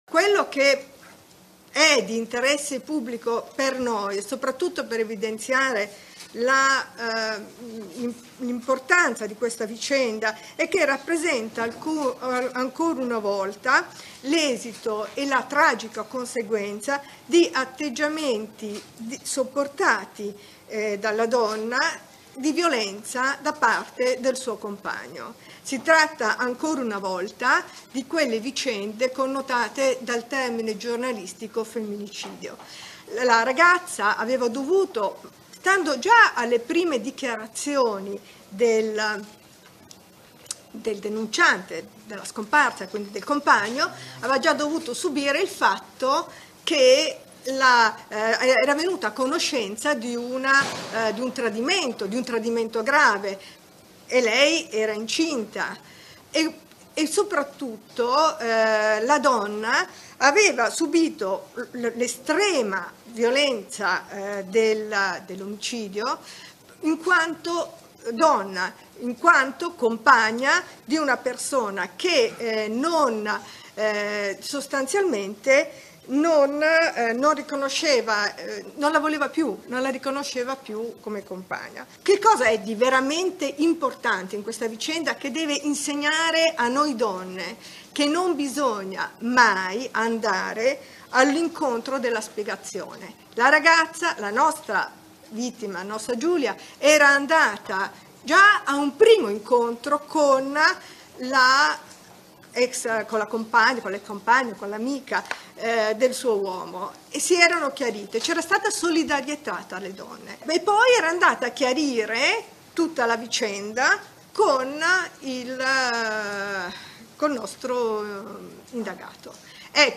Non commettere quell’errore: non andate all’ultimo appuntamento, questo l’appello del Procuratore aggiunto di Milano Letizia Mannella